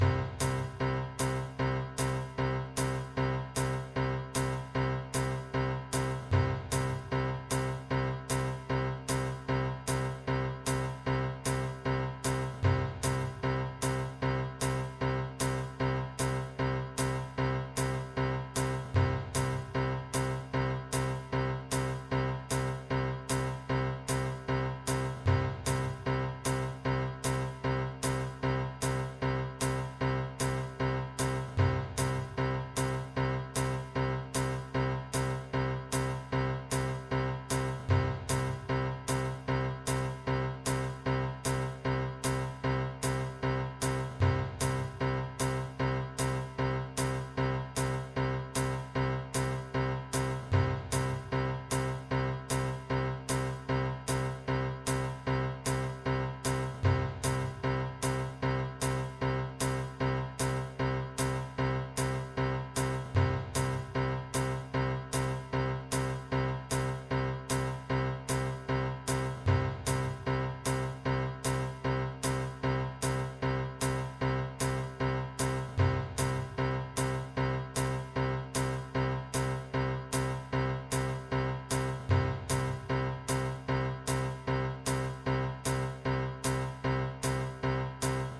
muziek-verhalen-groep-5-6-bijlage-13-begeleidingsritme-spreektekst.mp3